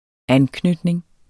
Udtale [ ˈanˌknødneŋ ]